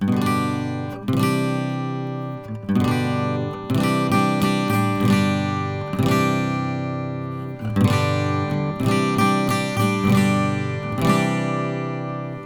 Prise de son 1 :  LCT640 – Neumann U87.
Pour les prises de son, j’ai utilisé un préamplificateur Neve 4081 quatre canaux avec la carte optionnelle Digital l/O qui convertit l’analogique en numérique AES sur une SubD25 ou en Firewire.
Les échantillons n’ont subi aucun traitement.
Enregistrements d’une guitare acoustique (AIFF) :
Lewitt640-Prise1.aif